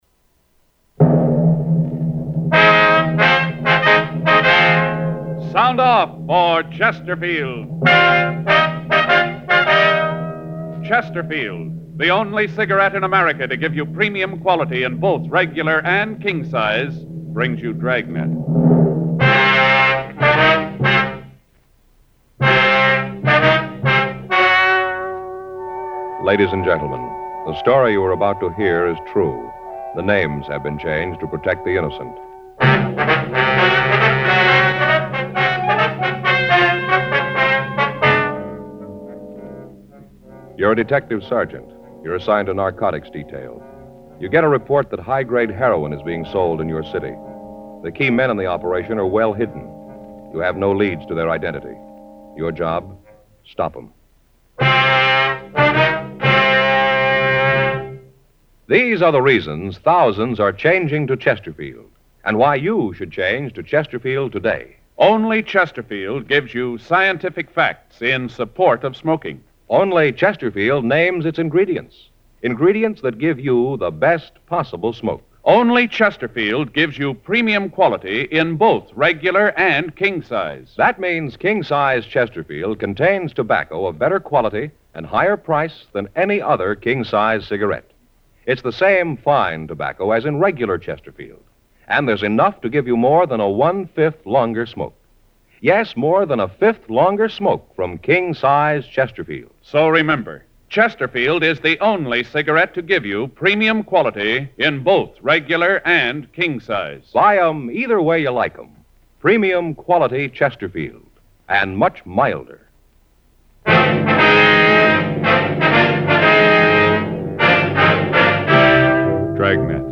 Dragnet is perhaps the most famous and influential police procedural drama in media history. The series gave audience members a feel for the danger and heroism of police work.